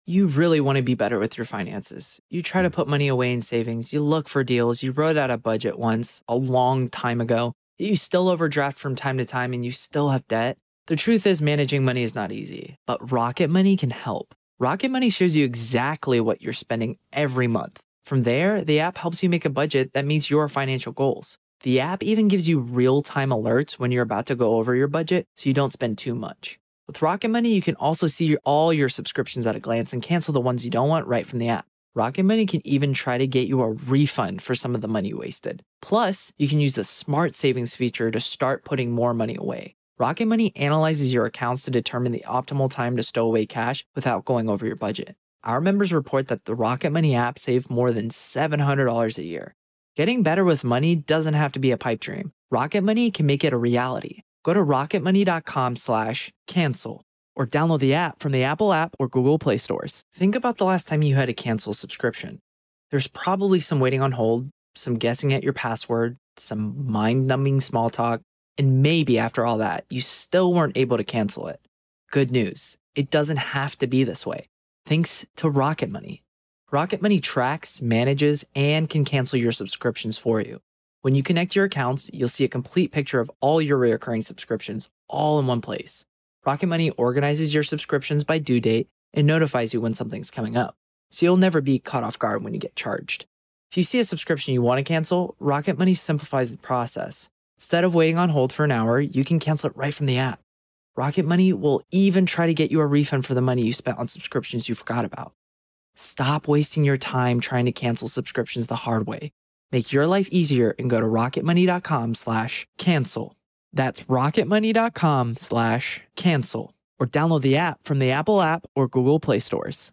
The story you've heard this week was narrated and produced with the permission of the respective author.